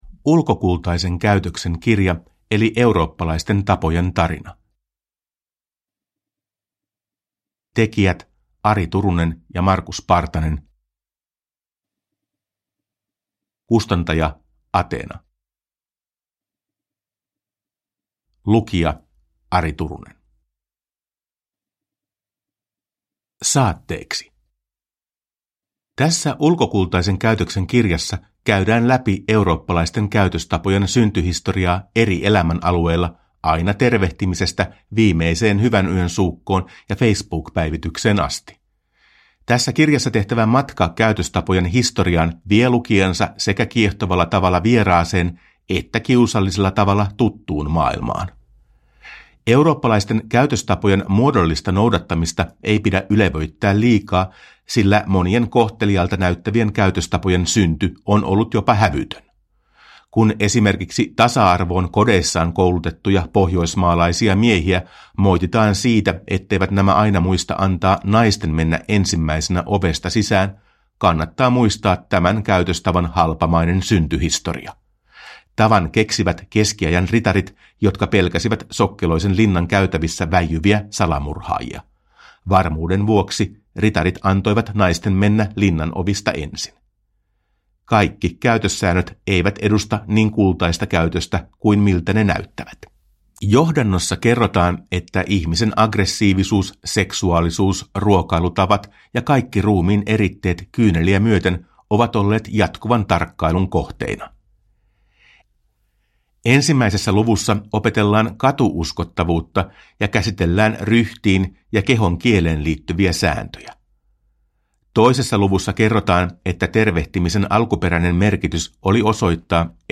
Ulkokultaisen käytöksen kirja – Ljudbok – Laddas ner